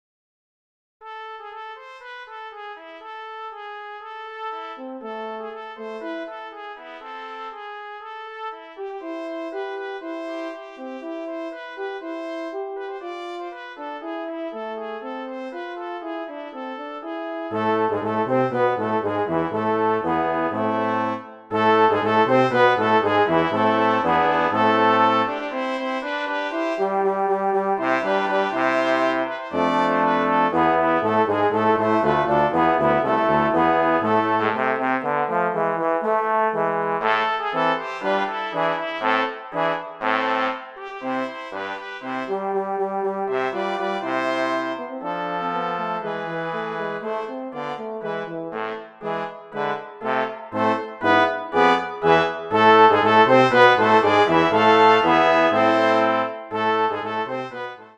Voicing: Brass Quintet